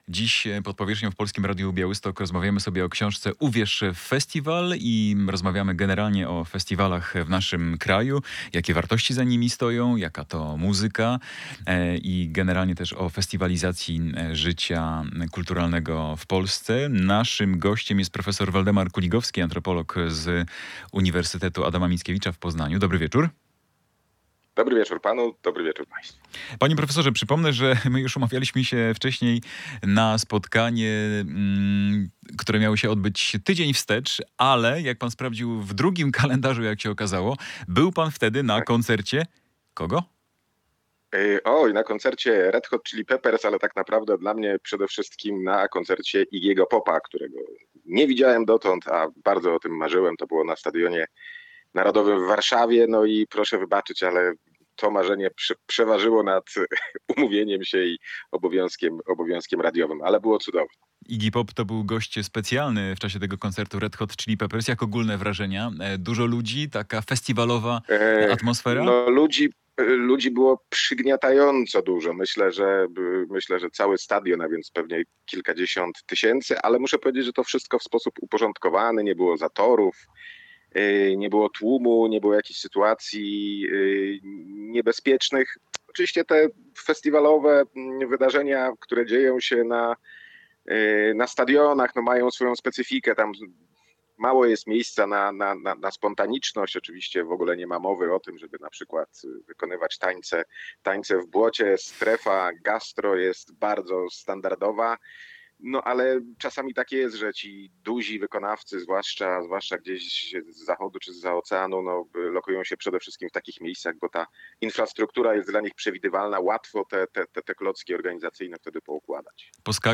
Polskie Radio Białystok